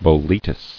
[bo·le·tus]